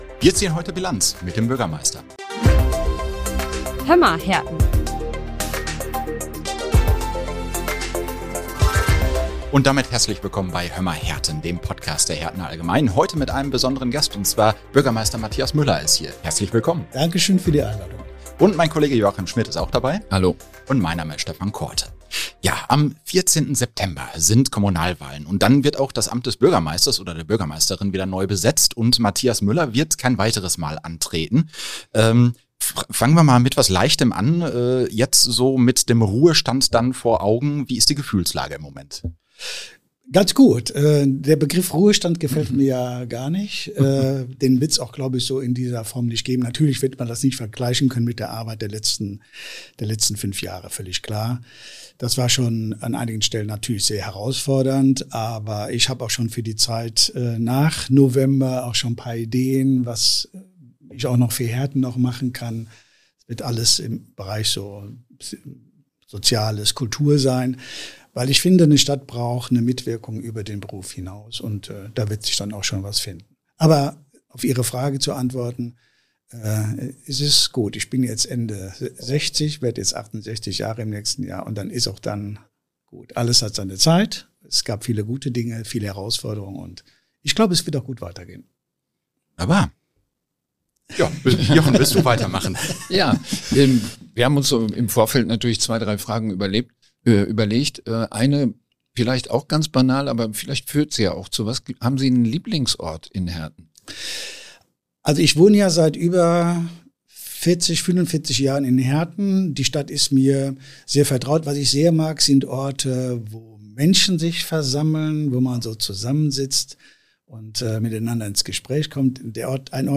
Wir ziehen Bilanz mit dem Bürgermeister - Gast: Matthias Müller - Hömma, Herten - Folge 20 ~ Hömma, Herten Podcast